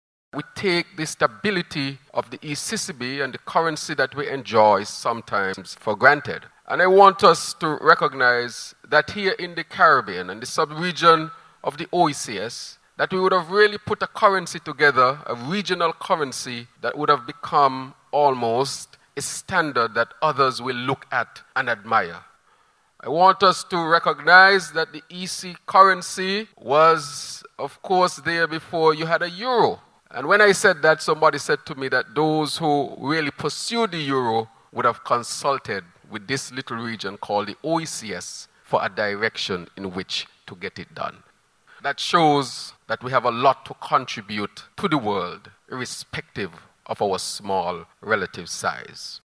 To commemorate its anniversary, a special Church Service was held at the Penticostal Church of God in St. Kitts.
Prime Minister, Dr. Terrance Drew who was in attendance of the service made these comments regarding the ECCU 39th anniversary: